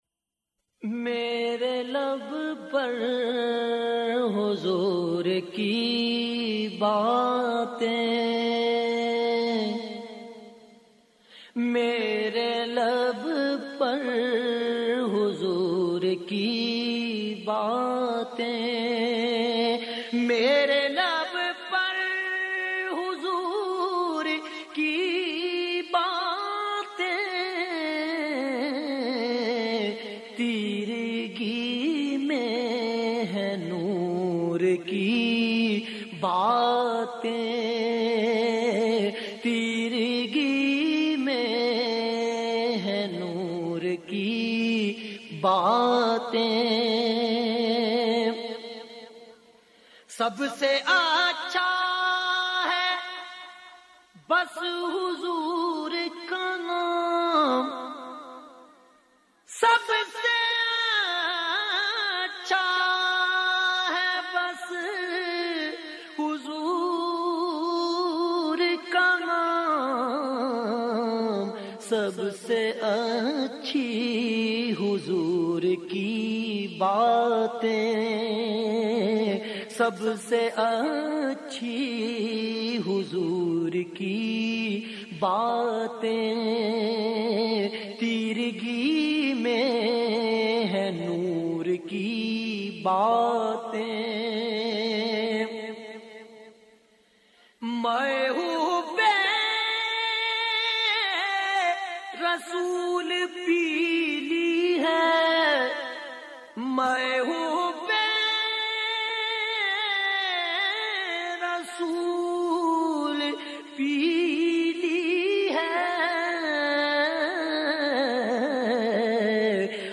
نعت رسول مقبول صلٰی اللہ علیہ وآلہ وسلم